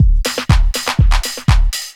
Detroit Beat 1_122.wav